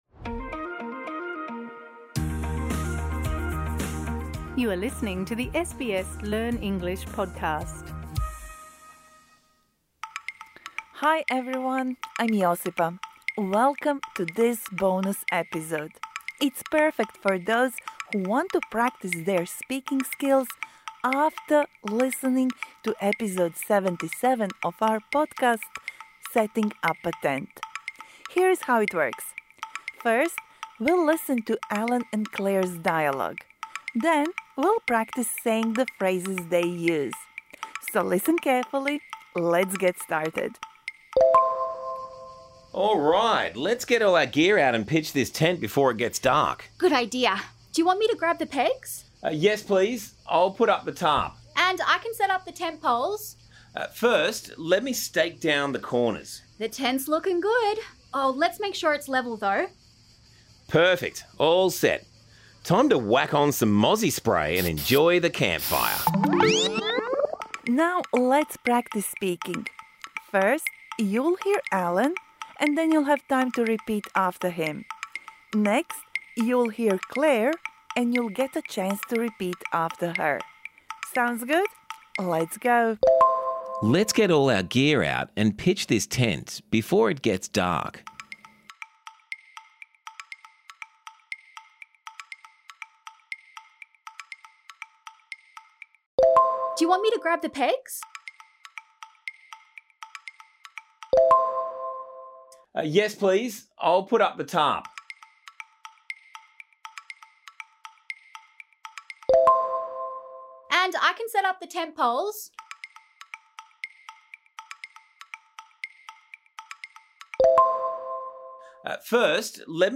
This bonus episode provides interactive speaking practice for the words and phrases you learnt in Episode #77: Setting up a tent.